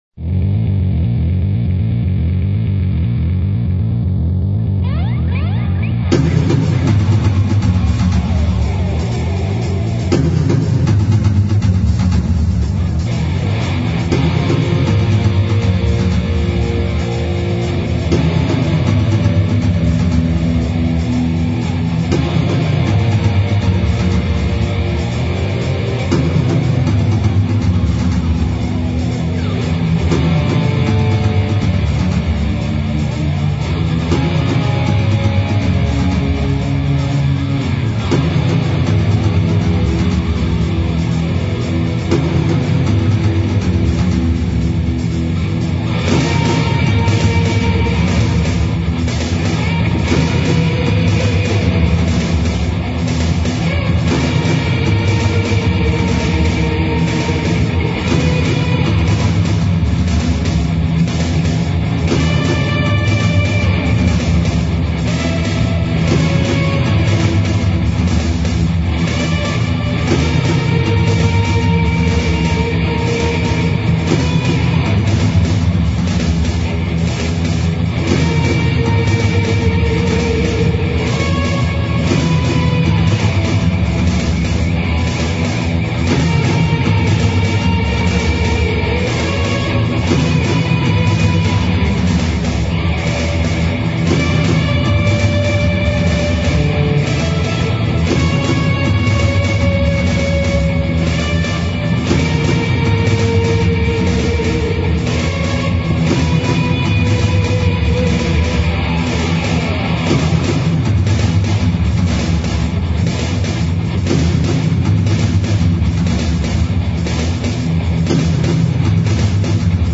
ícono del heavy metal